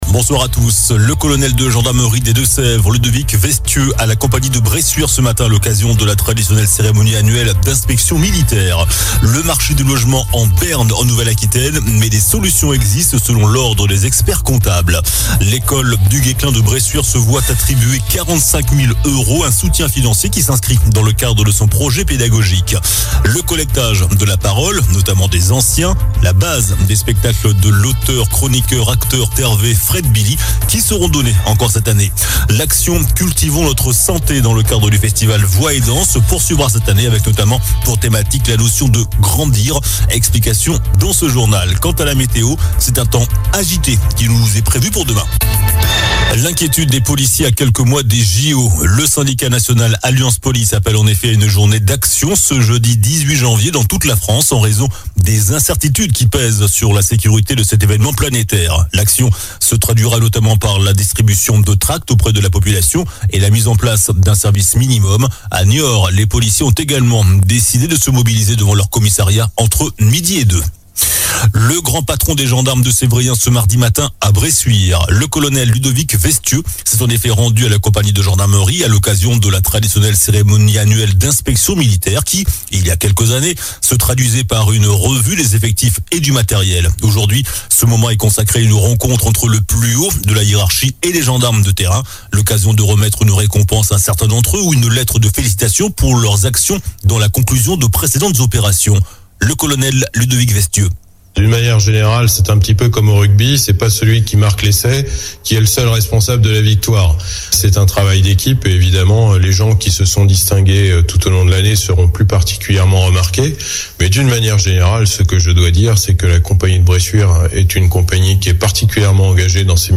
infos locales